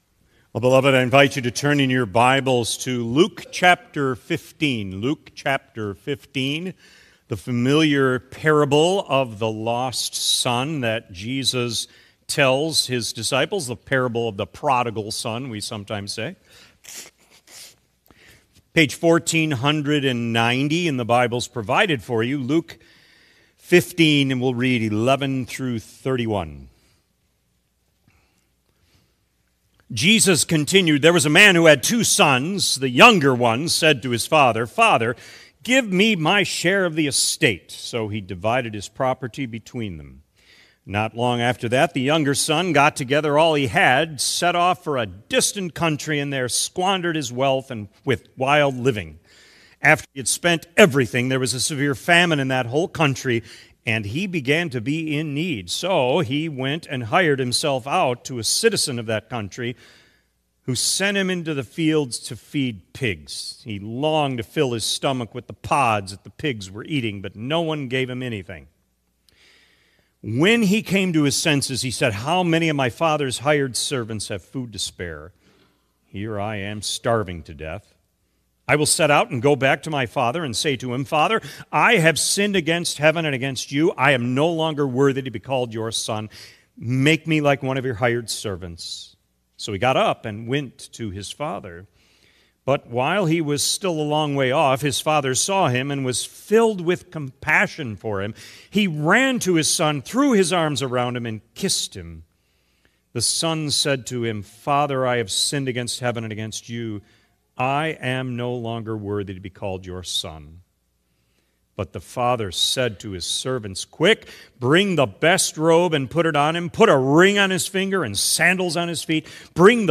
This week’s sermon invites us into the familiar story of the prodigal son through the powerful theme “There and Back Again.”